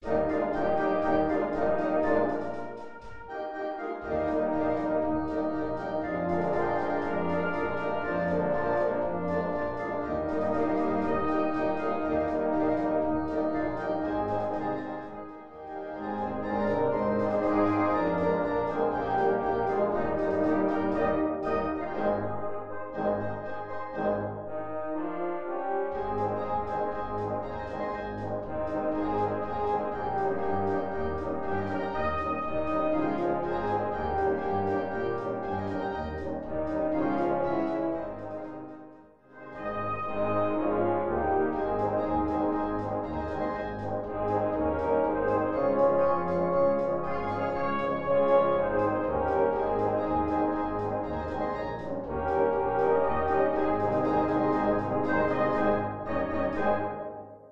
einfache, gut klingende Polka